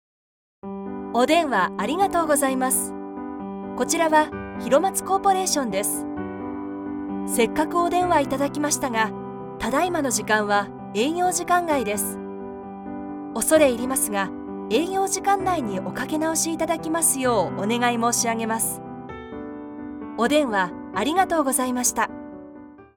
IVR
Con mi estudio en casa equipado con equipos de primer nivel, estoy lista para darle vida a tu proyecto.
Una voz nítida que cautiva a tu audiencia
Conversacional, versátil, elegante, lujosa, auténtica, autoritaria, conversacional, profunda, confiable, optimista, amigable y creíble.
Micrófono: NEUMANN TLM102